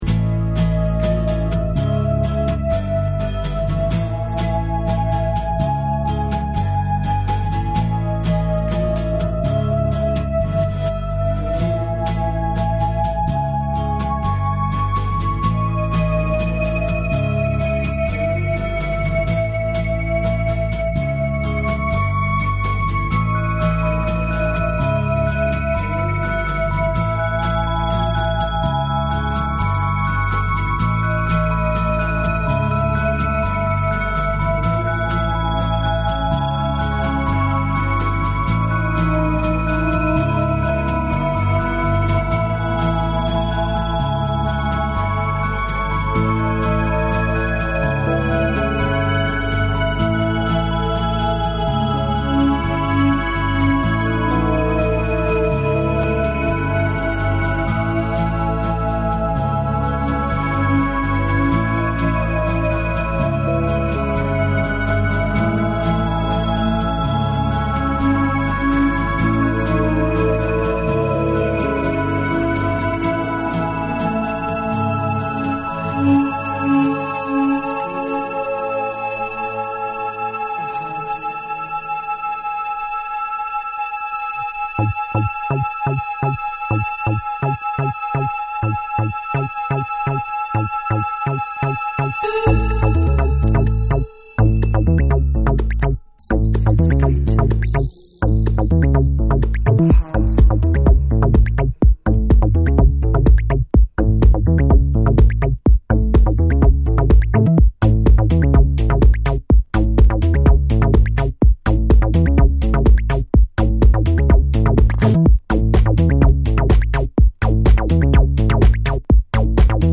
オリジナルのドリーミーな雰囲気を残しテッキーなハウス・サウンドに仕立てた3。